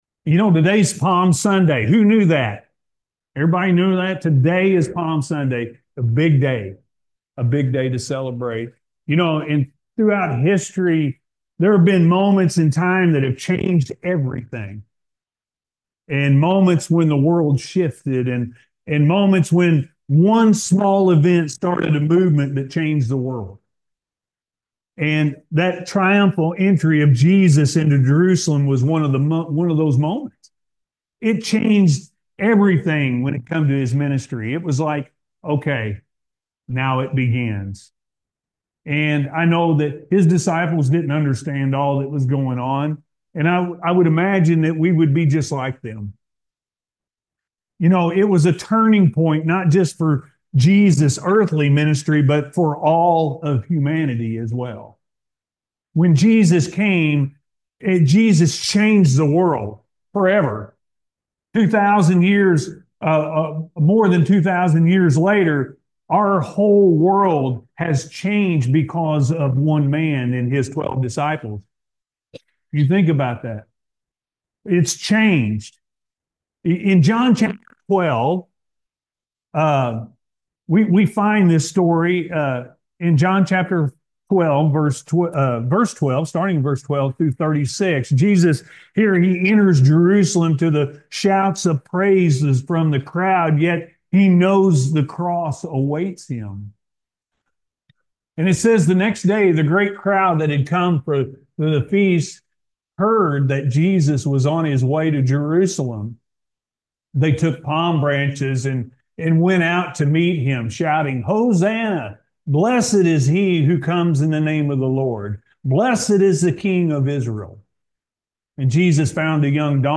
Jesus Came To Change The World-A.M. Service – Anna First Church of the Nazarene